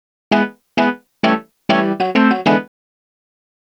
Swinging 60s 4 Organ-E.wav